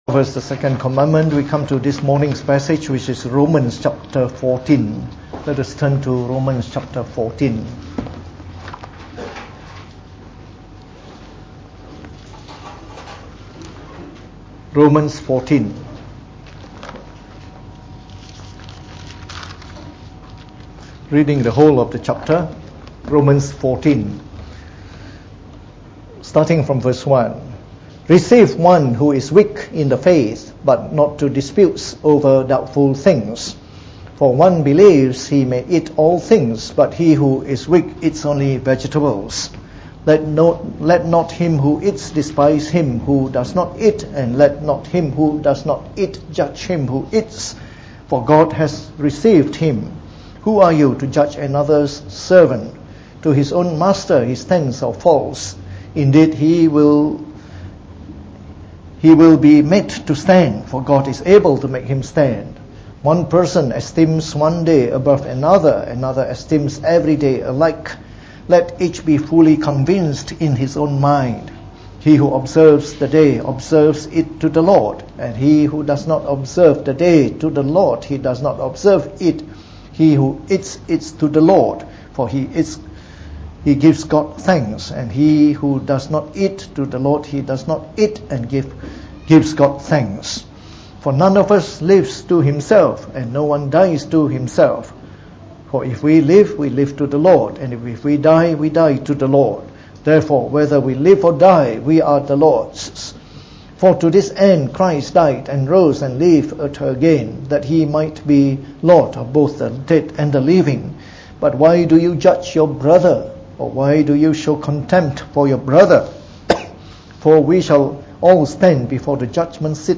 Preached on the 14th of October 2018.
delivered in the Morning Service